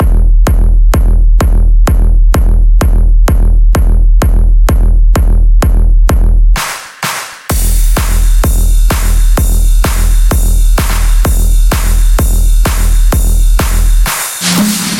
一个基本的鼓声循环
它包括一个上踢腿，一些第二踢腿的拍子，和一些有点像人类的hihats。它适用于所有的调，并为128 BPM设计。
标签： 128 bpm Electronic Loops Drum Loops 2.53 MB wav Key : Unknown
声道立体声